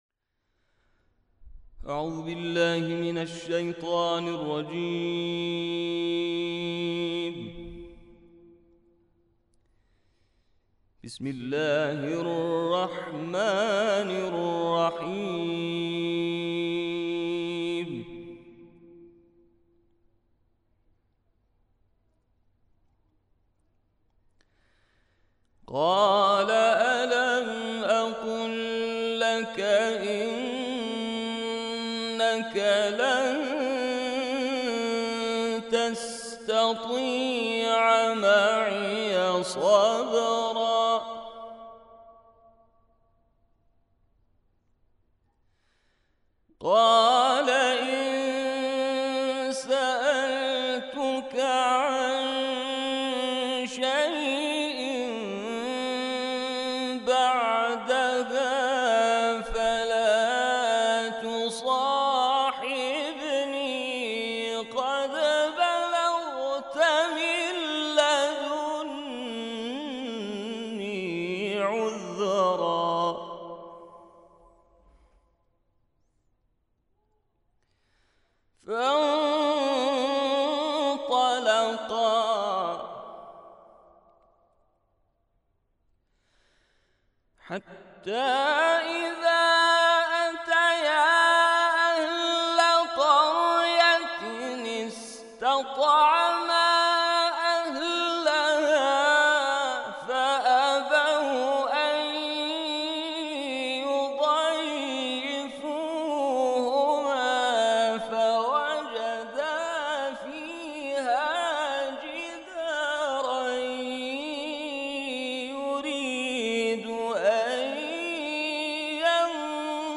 مدت زمان این تلاوت استودیویی 5 دقیقه است.